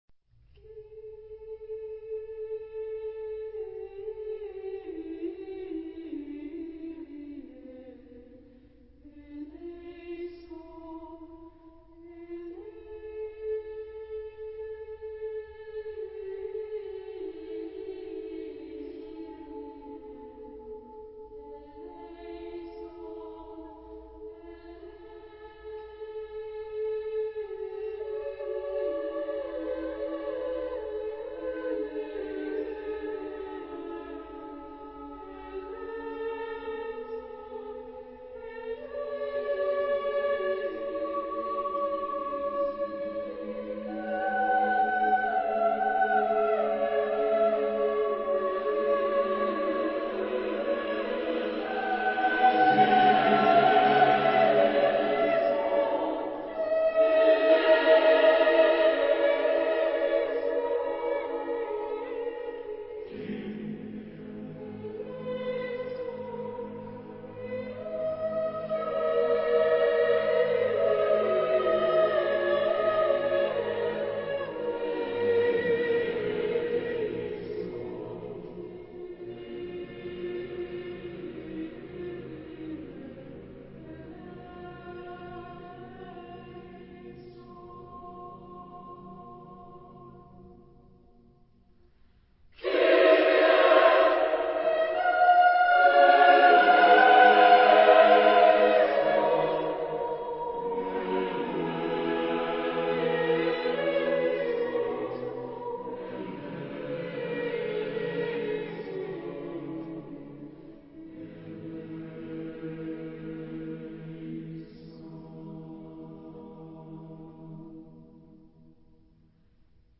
Genre-Style-Forme : Sacré